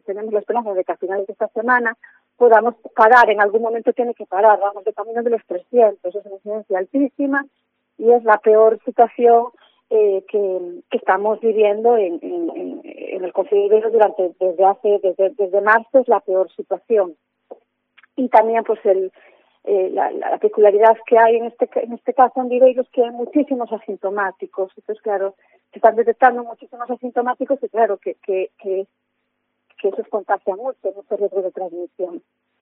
Declaraciones de MARÍA LOUREIRO, alcaldesa de Viveiro